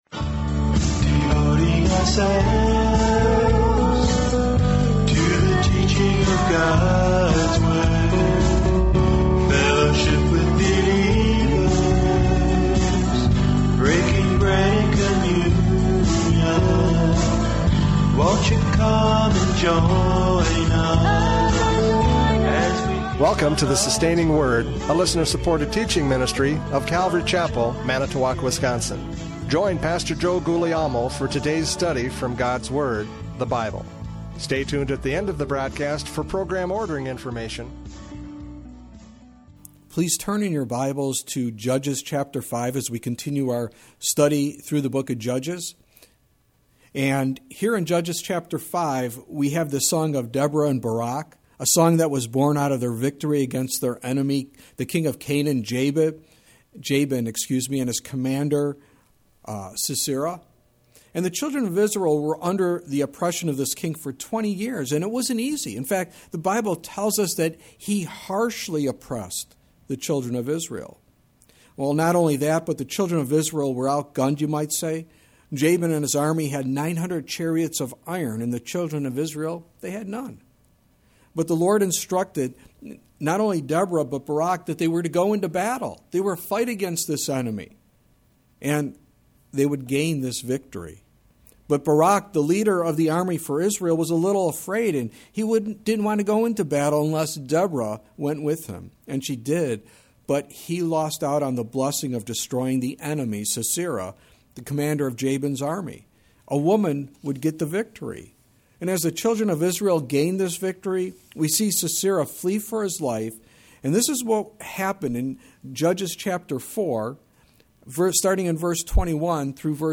Judges 5:10-12 Service Type: Radio Programs « Judges 5:6-8 Life Under Oppression!